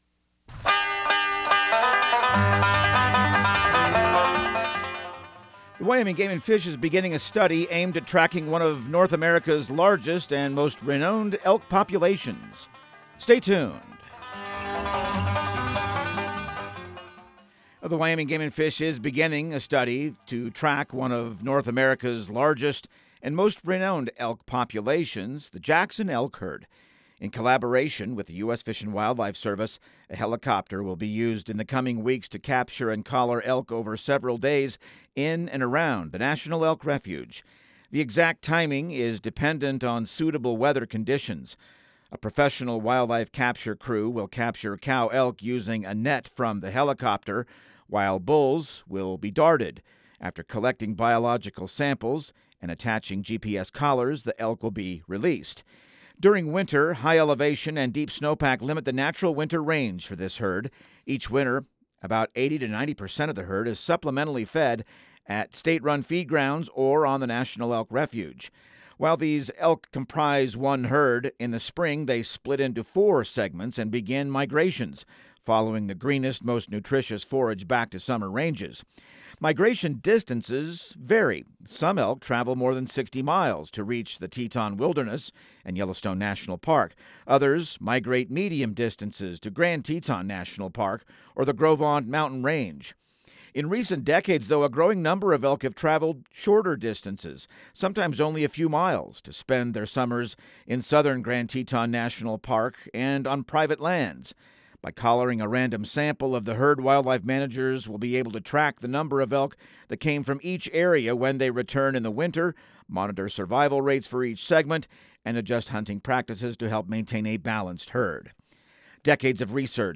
Radio news | Week of January 20